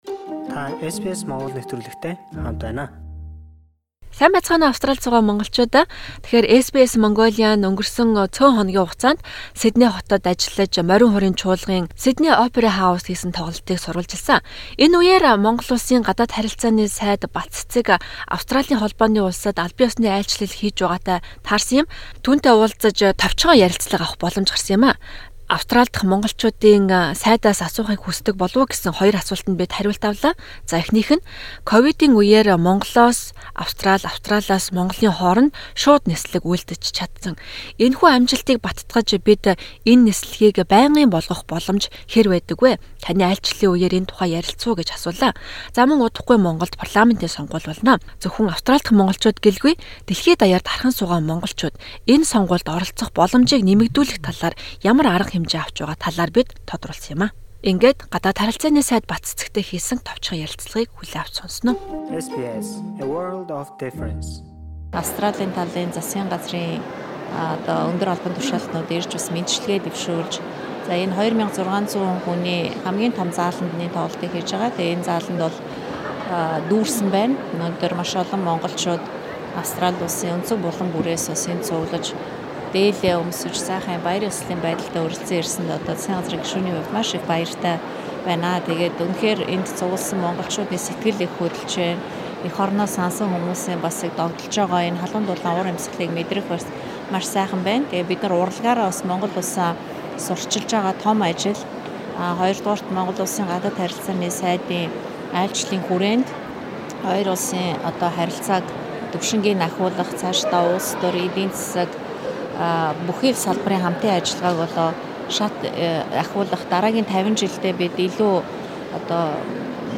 Монгол улсын Гадаад харилцааны сайд Б.Батцэцэгтэй цөөн хором ярилцаж, Морин хуурын чуулгын тоглолтын тухай болон хоёр орны хооронд шууд нислэг хийх боломж, гадаадад байгаа иргэдийн сонгуульд оролцох боломжийг нэмэгдүүлэх ямар бодлого барьж байгаа талаар тодрууллаа.